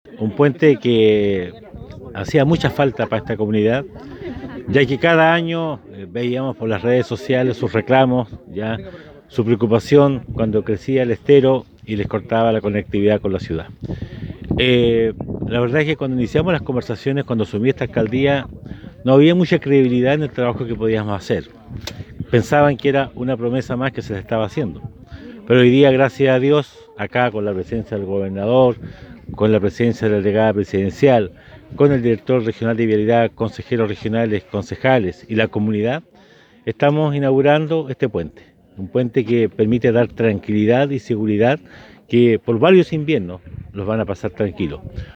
Durante el pasado fin de semana se realizó la ceremonia de inauguración de la reposición del Puente San Francisco, un anhelado proyecto por parte de los vecinos del sector distante a 8 kilómetros de Osorno por la Ruta 5 hacia el norte.
El Alcalde Carrillo destacó el trabajo conjunto entre el municipio y la dirección de vialidad en la región de Los Lagos, pues esta era una necesidad que tenía este sector rural, que permite dar tranquilidad y seguridad a la comunidad.